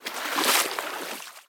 water-01.ogg